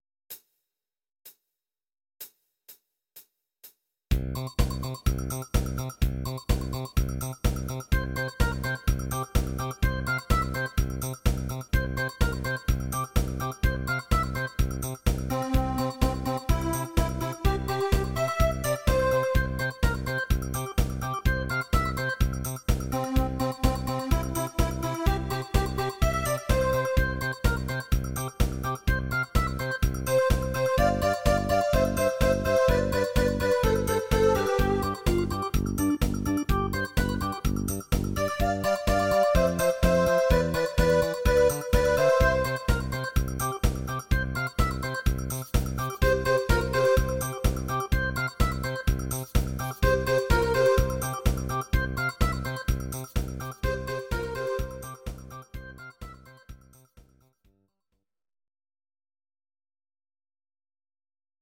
Audio Recordings based on Midi-files
Pop, Disco, 1980s